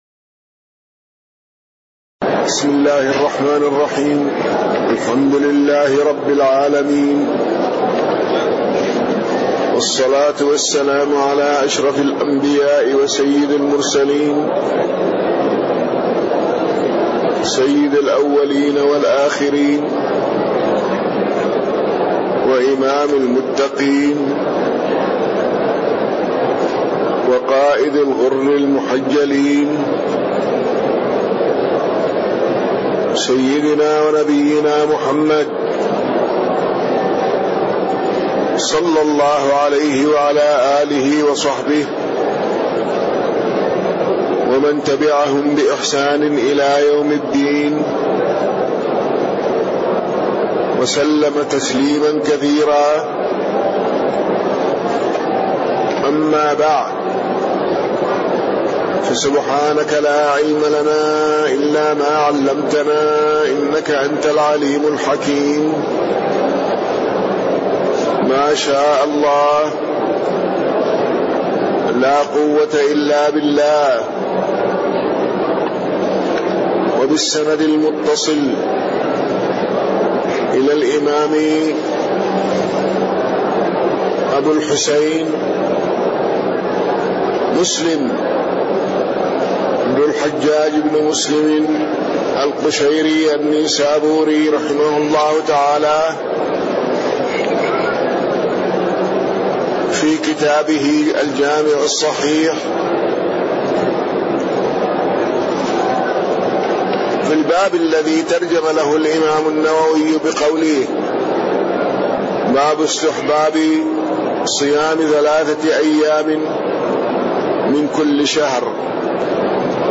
تاريخ النشر ١٩ شعبان ١٤٣٣ هـ المكان: المسجد النبوي الشيخ